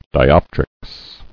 [di·op·trics]